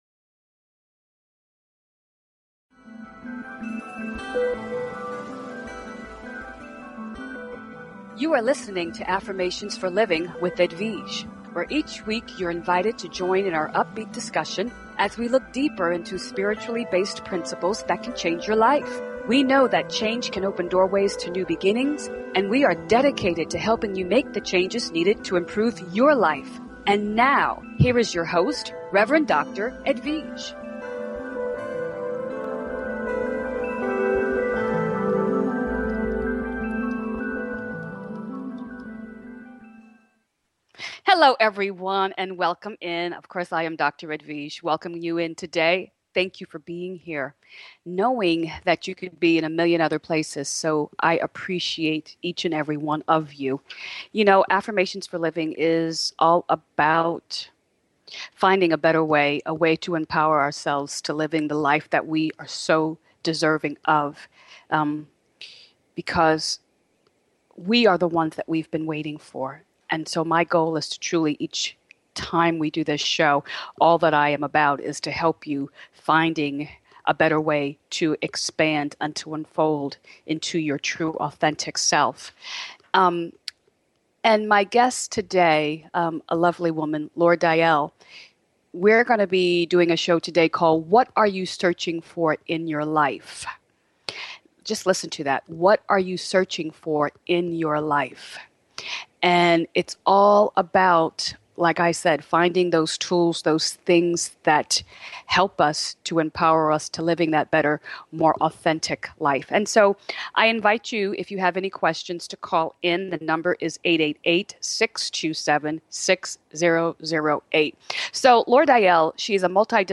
Each week you’re invited to join in on our upbeat discussion as we look deeper into spiritual based principles that can change your life.